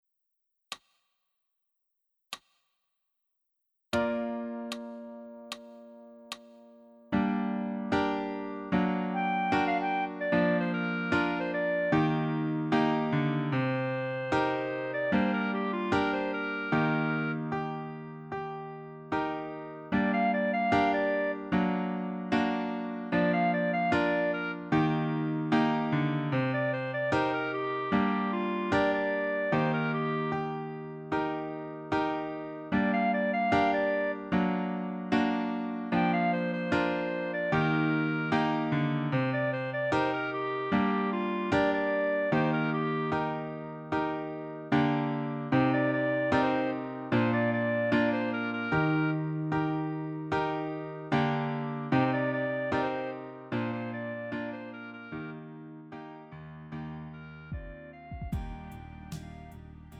음정 -1키 3:25
장르 pop 구분 Lite MR